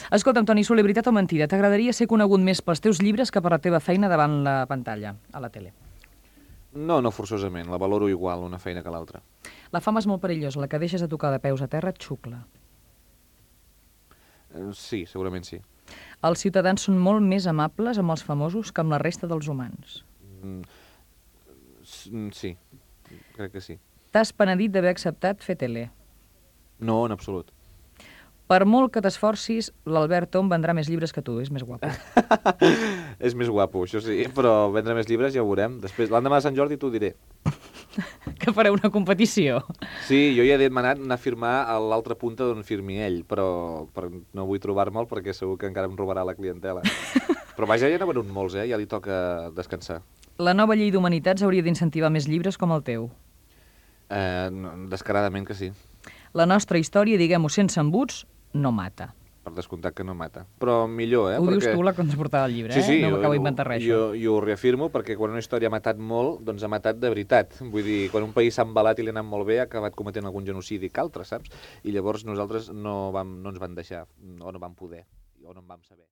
Entrevista a l'escriptor i presentador Toni Soler
Entreteniment